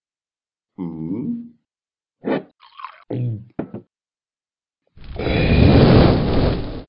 Talking Ben Dragon Fire Sound Effect Free Download